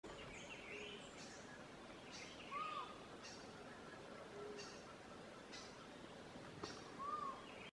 Collared Forest Falcon (Micrastur semitorquatus)
Life Stage: Adult
Location or protected area: Parque Nacional Calilegua
Condition: Wild
Certainty: Recorded vocal
halcon-montes.mp3